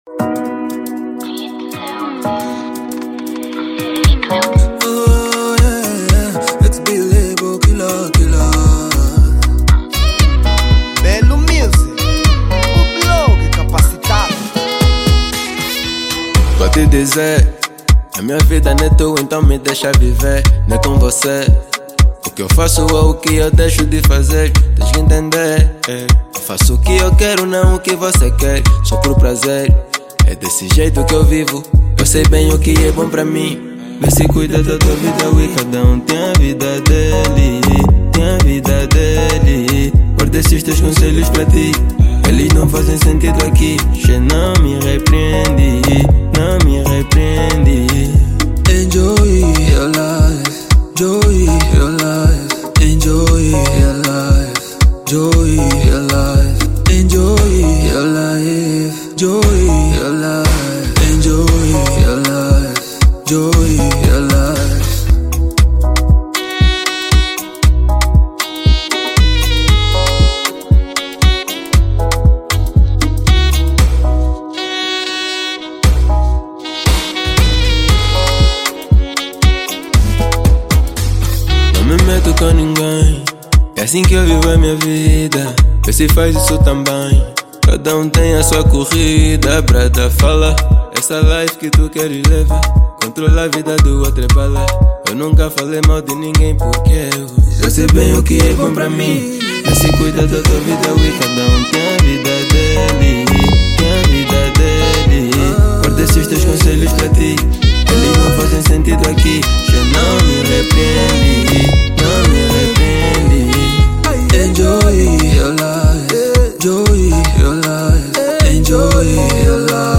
Género : Afro Pop